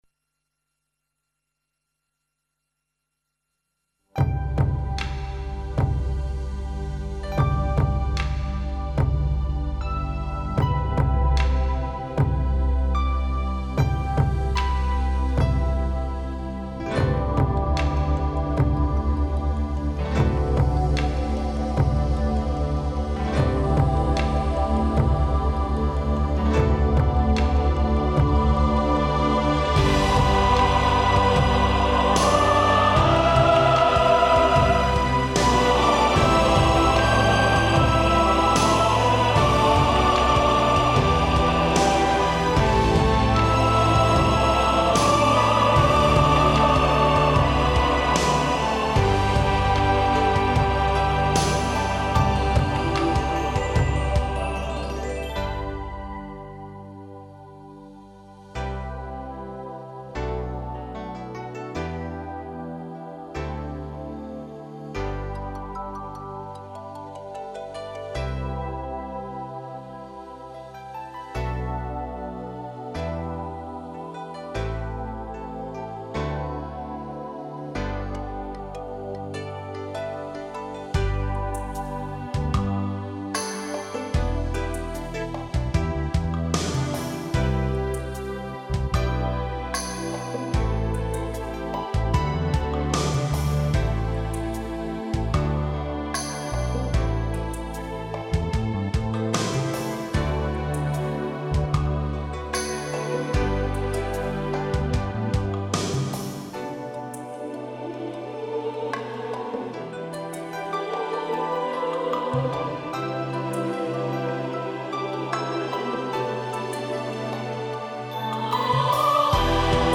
0094-梅花三弄G调伴奏.mp3